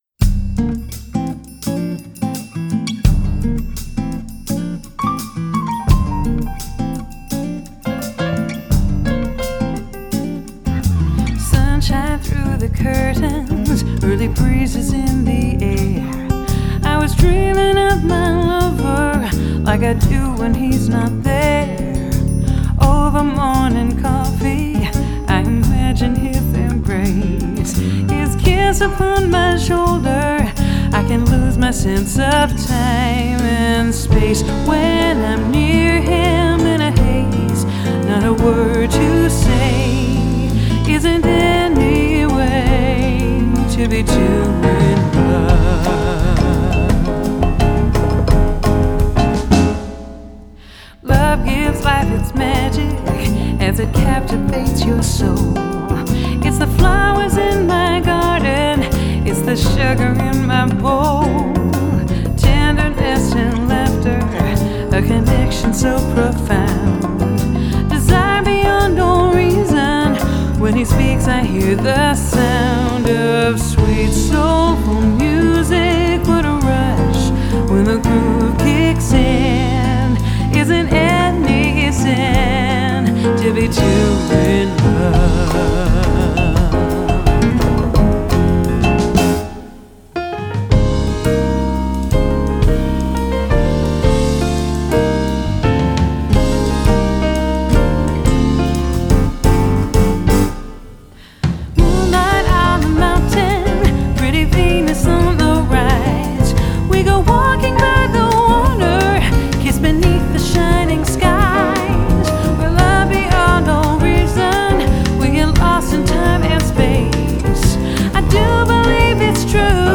Genre: Vocal Jazz